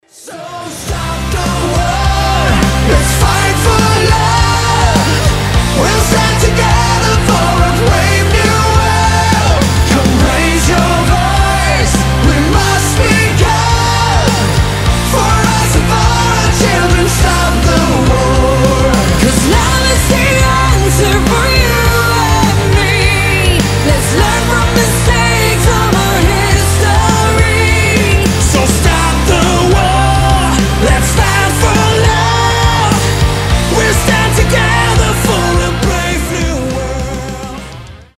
рок , alternative rock , тяжелый рок